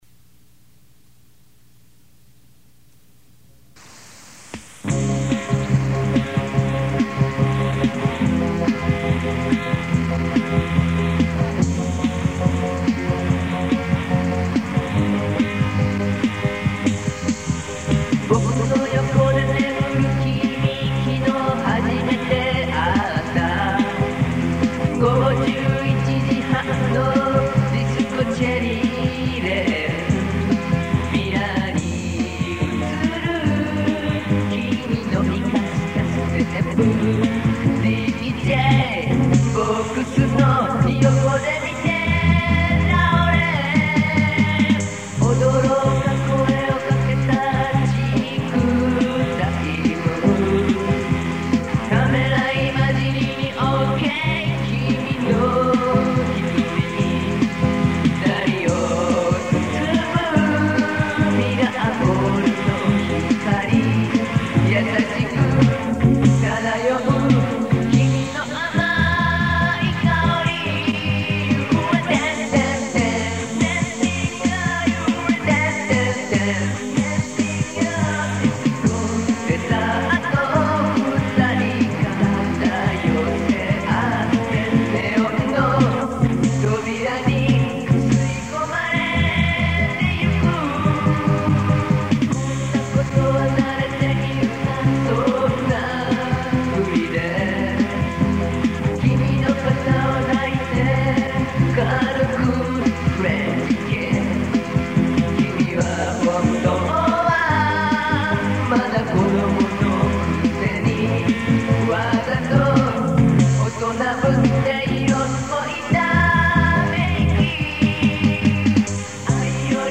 ギター
ベース
ピアノ
キーボード
ドラムプログラム